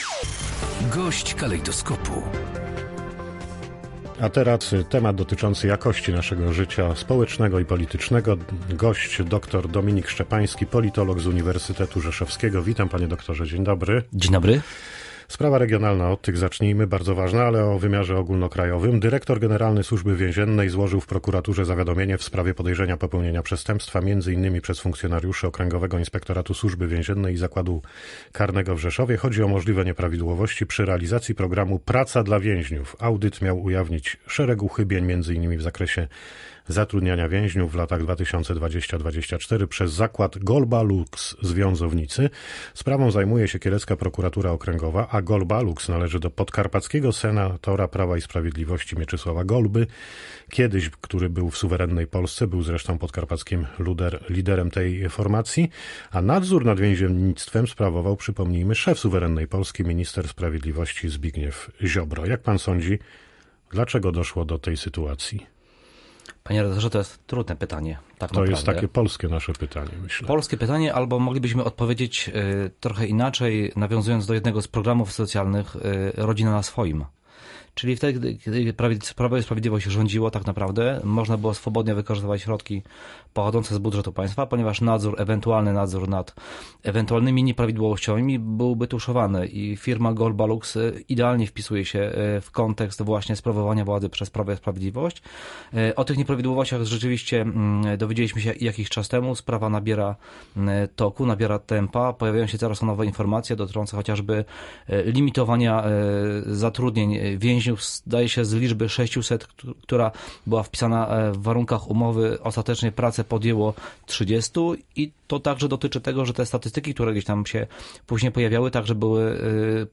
GOŚĆ DNIA.